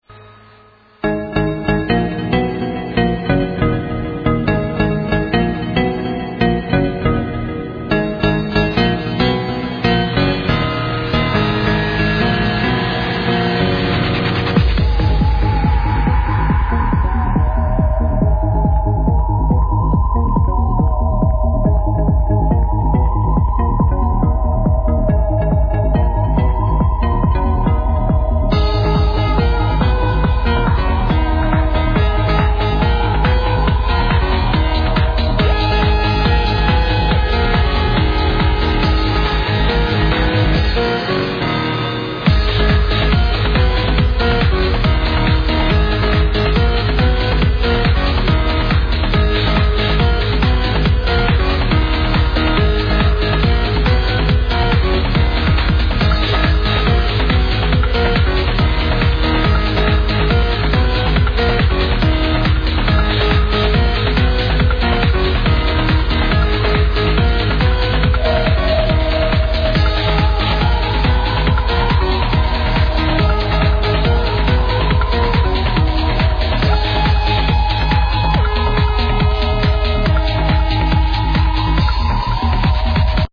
Trance With Beautiful Piano
Genre Trance